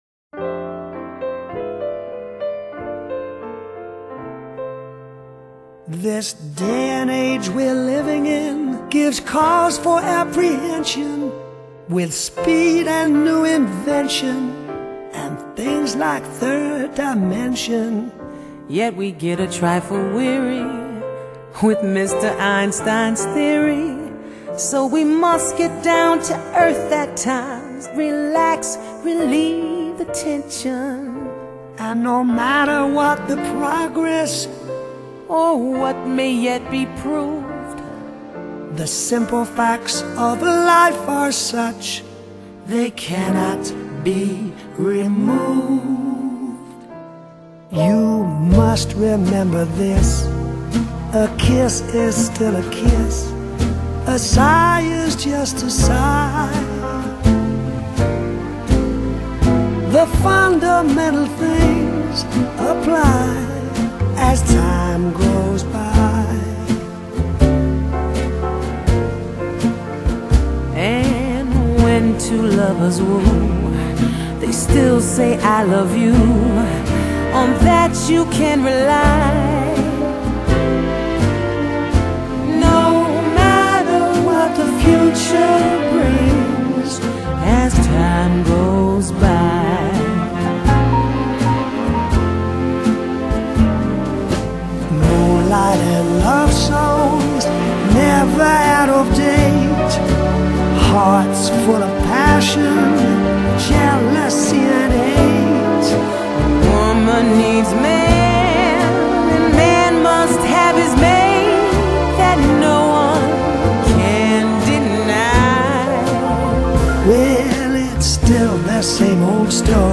爵士美國情歌
以風格統一的性感白人爵士，演唱世間男女傳唱的愛戀情歌經典。